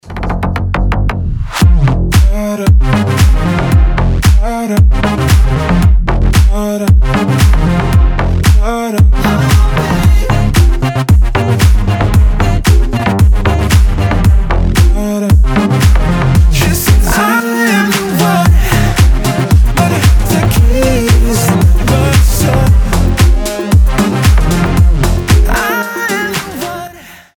• Качество: 320, Stereo
ритмичные
басы
house
ремиксы